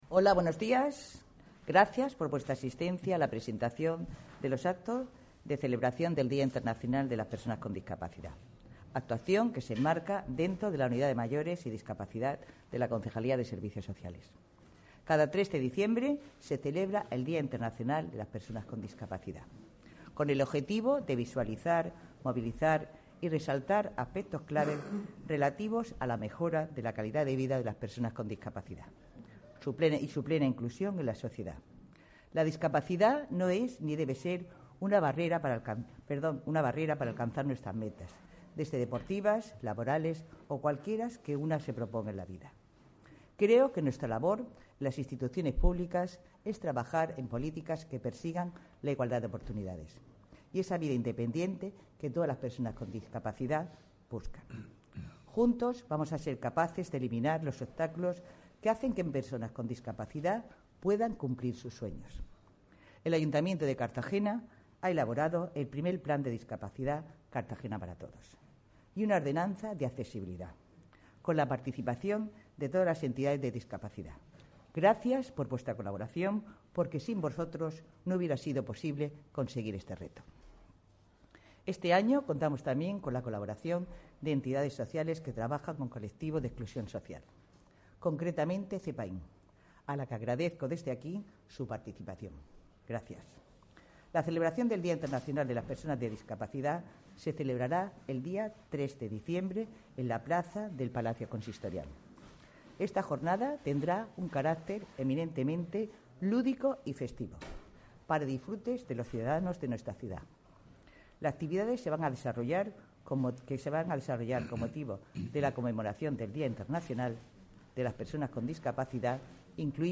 Presentación del Día Internacional de las Personas con Discapacidad